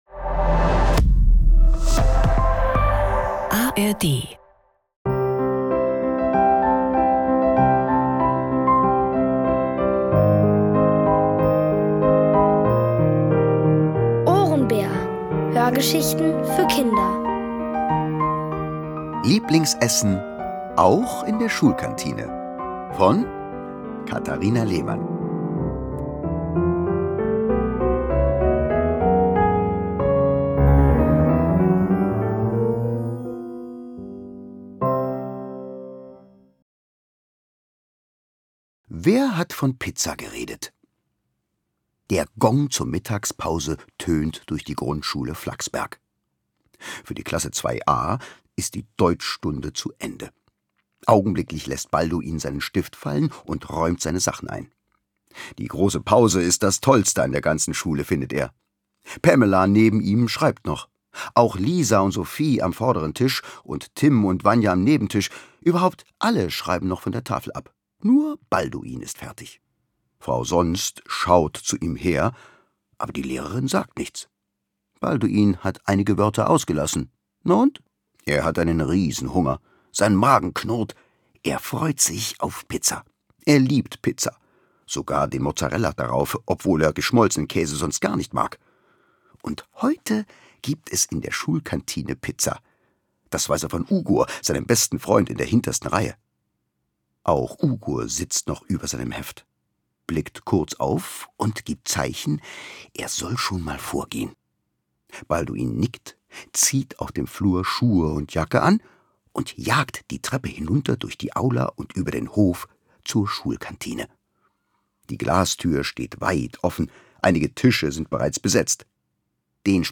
Es liest: Thomas Nicolai.